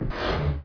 drag3.wav